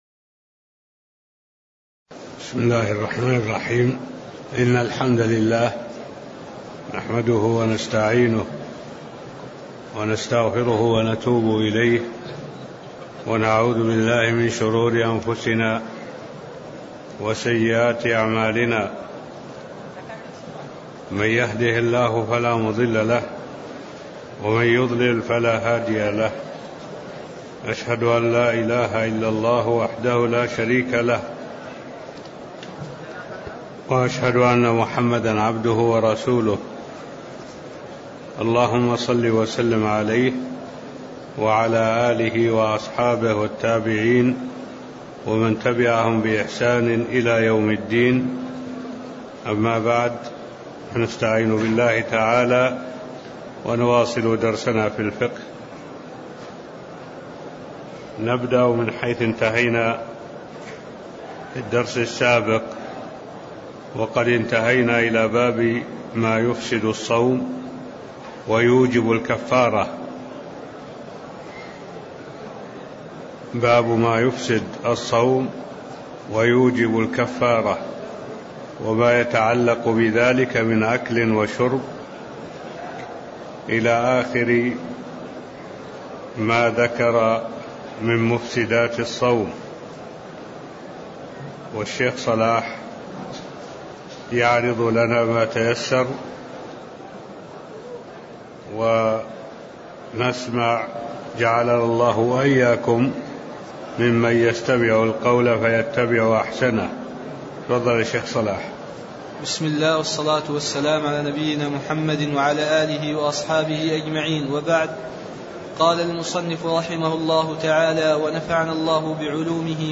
المكان: المسجد النبوي الشيخ: معالي الشيخ الدكتور صالح بن عبد الله العبود معالي الشيخ الدكتور صالح بن عبد الله العبود باب ما يفسد الصوم ويوجب الكفارة (01)